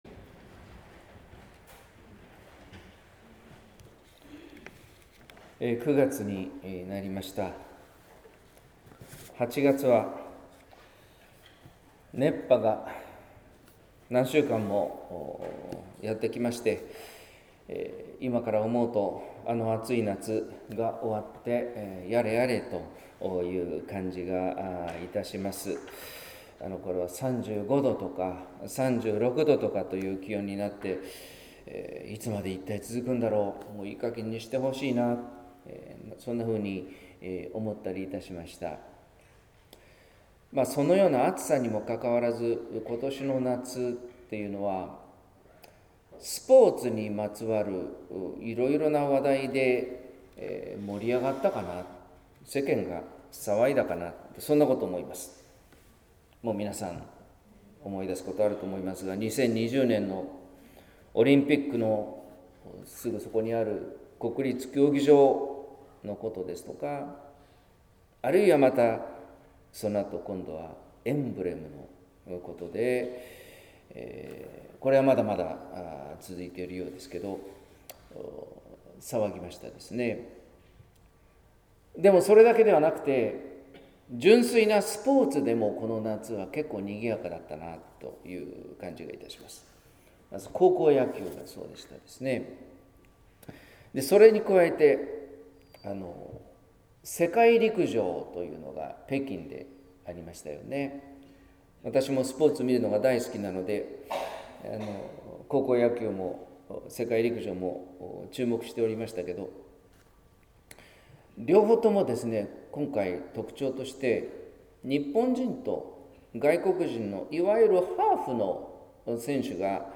説教「小犬のパン」（音声版） | 日本福音ルーテル市ヶ谷教会